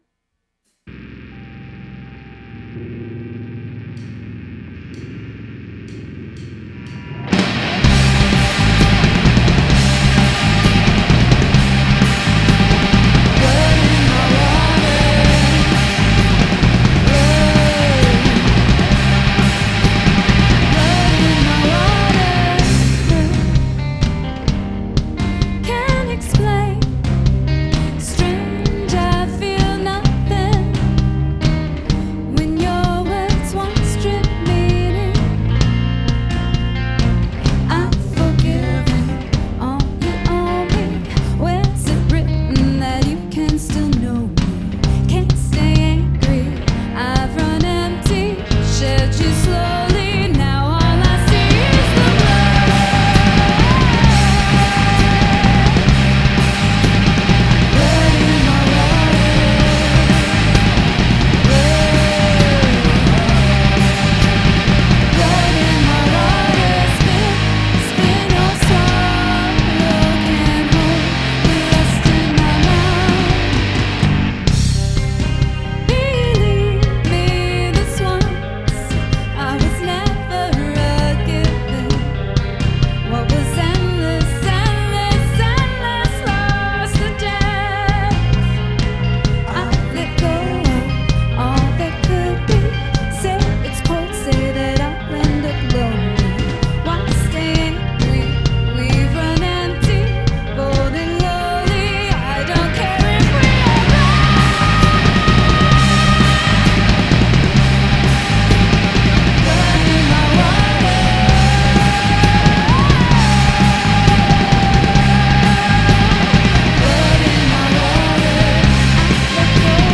They blend elements of Doom, Post-Punk and Art Rock.
guitar, vocals
drums, vocals